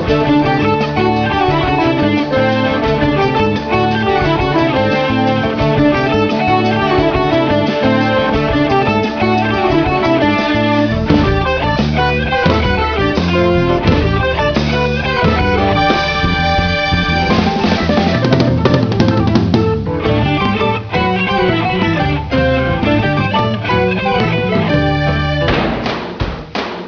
guitars
drums
vocals, bass and keyboards
recorded 8 track digital adat sep'95 through oct'96